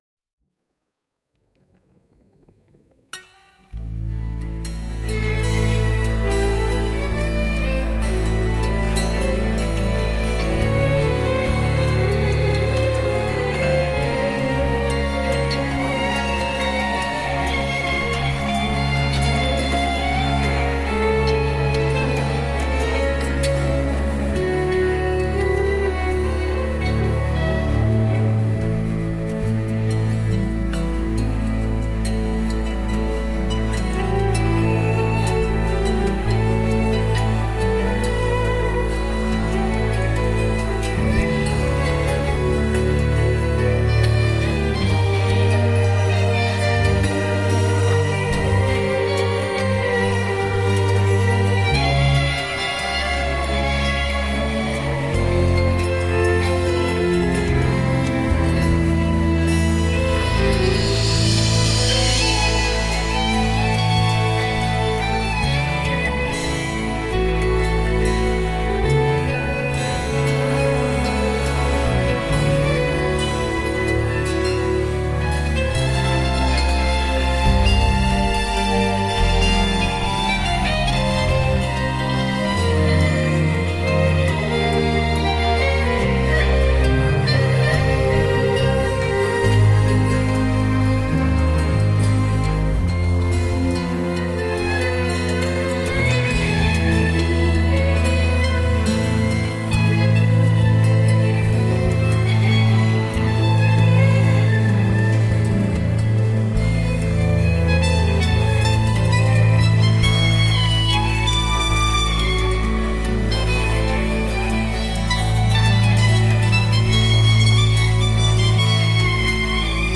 「ミステリアス」